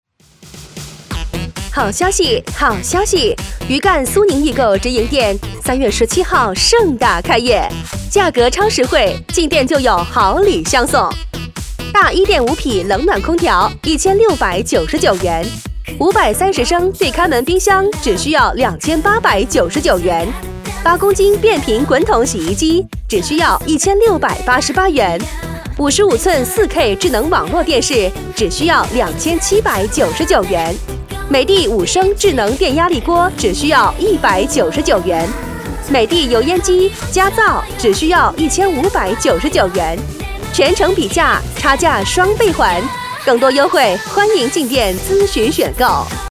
广告女35号（苏宁易购家电
激情力度 品牌广告